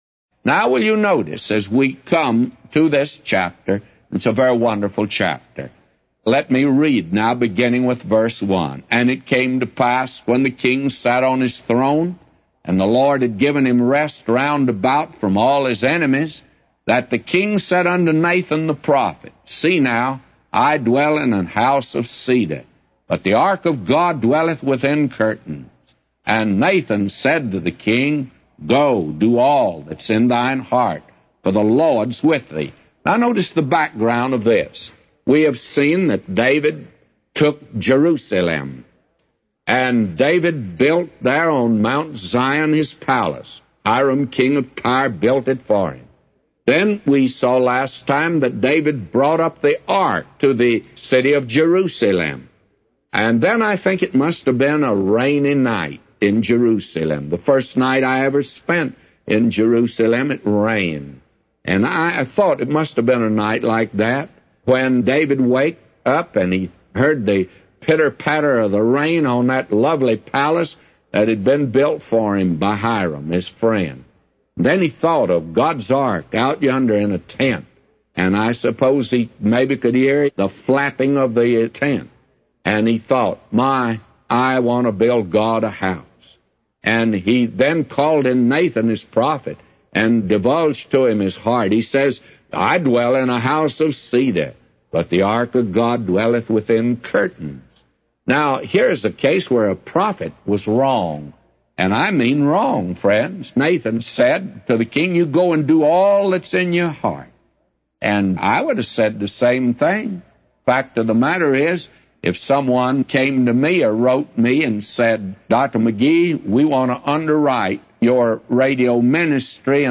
A Commentary By J Vernon MCgee For 2 Samuel 7:1-999